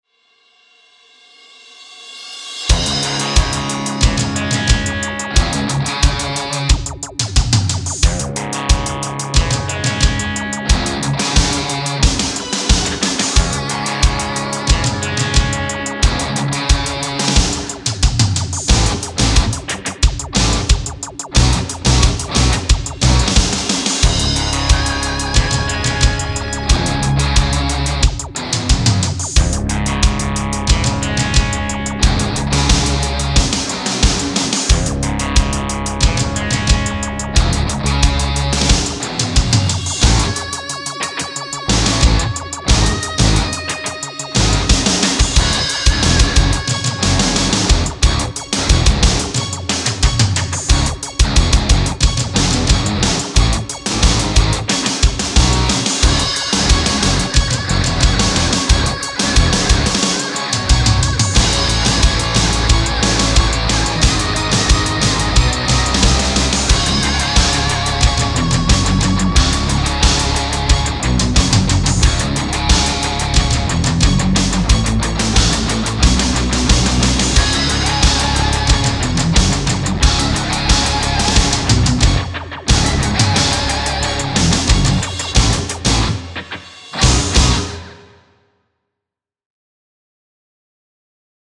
a soundtrack for a fictitious movie